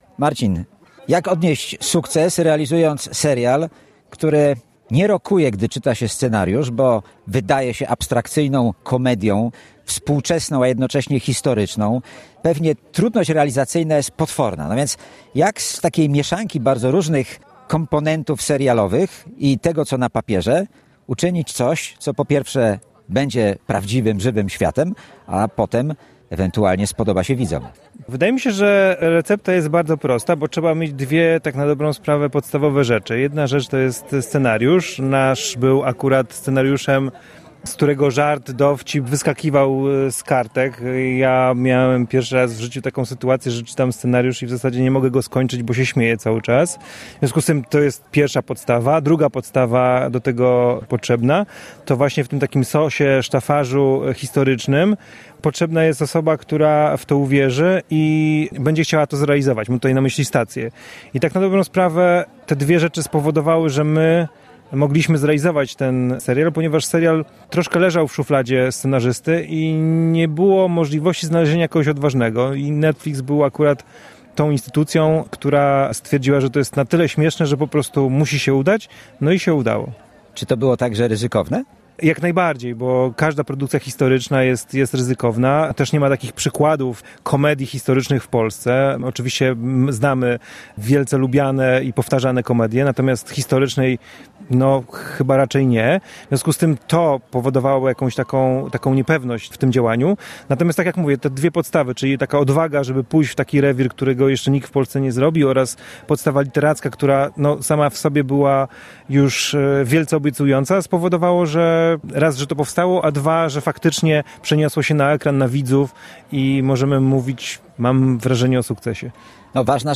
O serialu ‘’1670 ‘’ rozmawiają